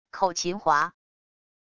口琴滑wav音频